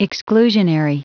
Prononciation du mot exclusionary en anglais (fichier audio)
Prononciation du mot : exclusionary